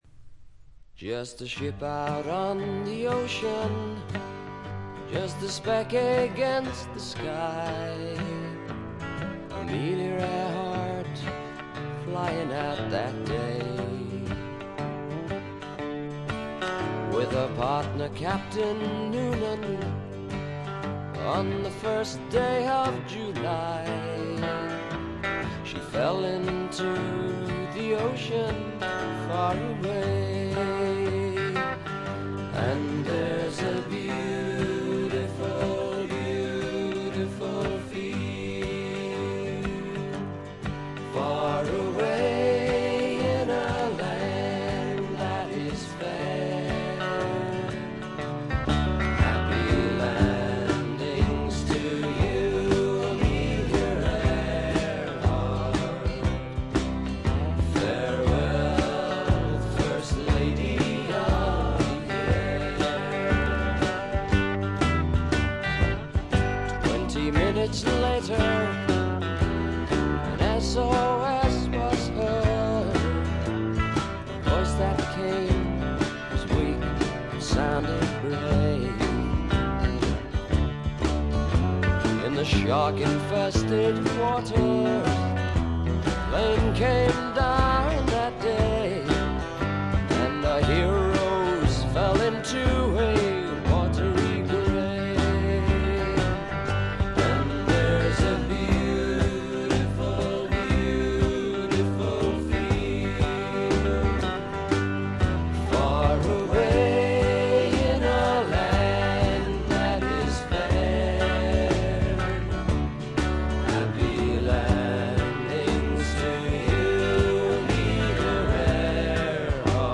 プカプカと牧歌的で枯れた味わいです。
mandolin, cello, mandola